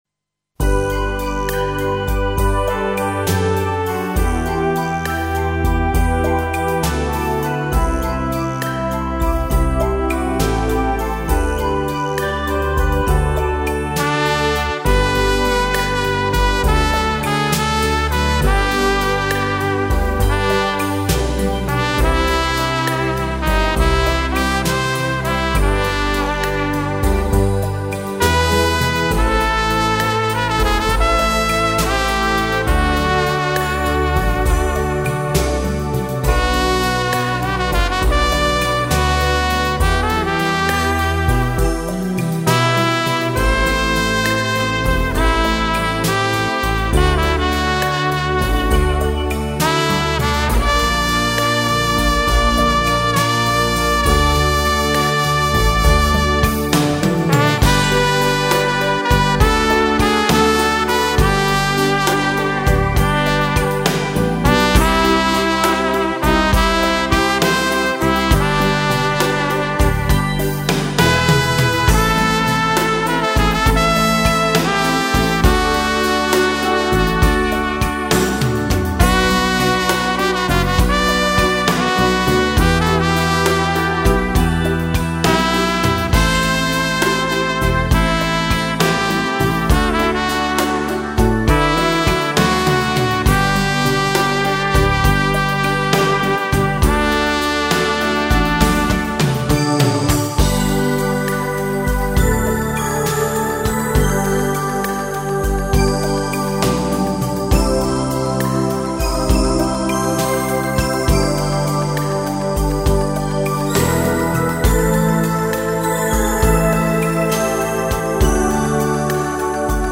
Genre: Jazz, Classical
Style: Easy Listening